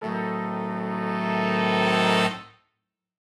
Index of /musicradar/gangster-sting-samples/Chord Hits/Horn Swells
GS_HornSwell-Adim.wav